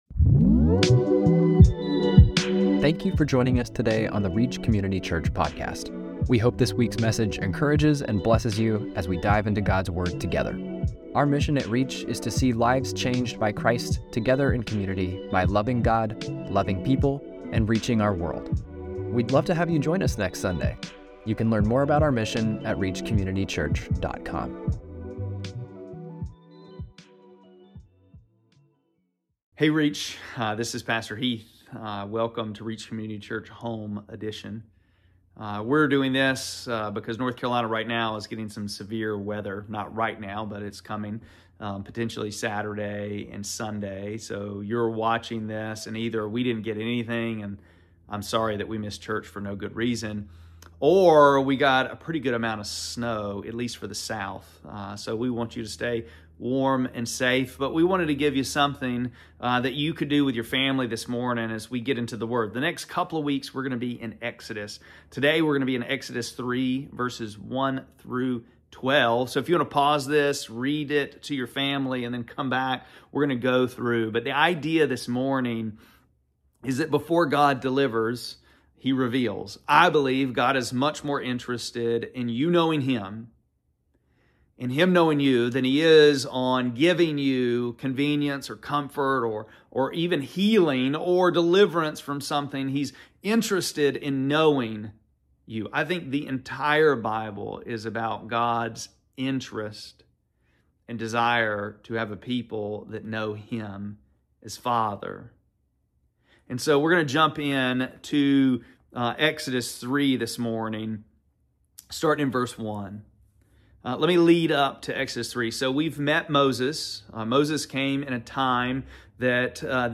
2-1-26-Sermon-.mp3